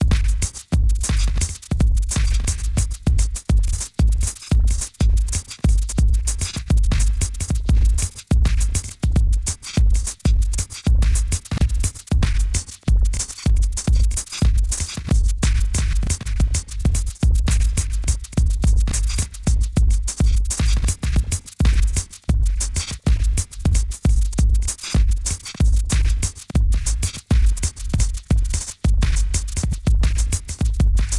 HouseLoop.wav